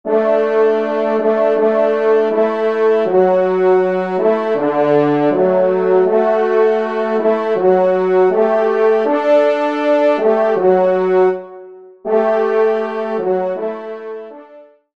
Genre : Fantaisie Liturgique pour quatre trompes
Pupitre 3° Trompe